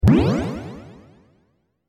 دانلود آهنگ موج 2 از افکت صوتی طبیعت و محیط
دانلود صدای موج 2 از ساعد نیوز با لینک مستقیم و کیفیت بالا
جلوه های صوتی